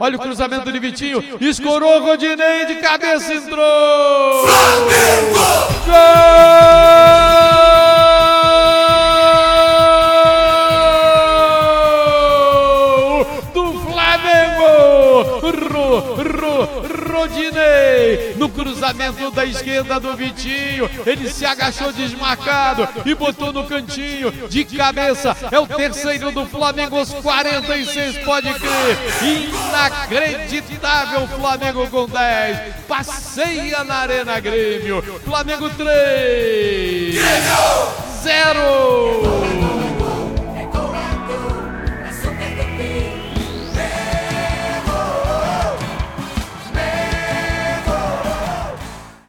Ouça os gols da vitória do Flamengo sobre o Grêmio com a narração de José Carlos Araújo